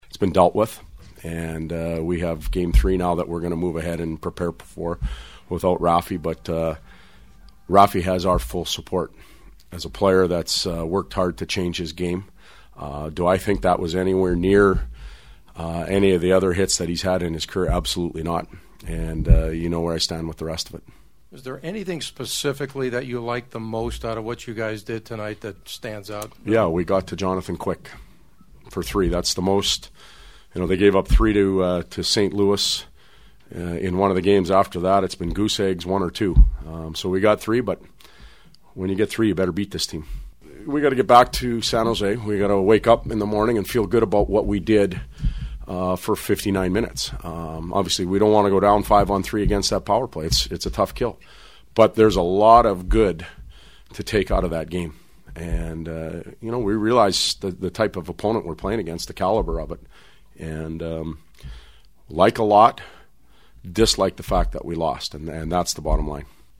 The sounds of the game from the locker room tell a story of a team that’s feeling relieved as much as joy after one of the great playoff wins in their club’s history.
Sharks coach Todd McLellan who began talking about the Raffi Torres suspension before I asked him about what he did like in his club’s play?: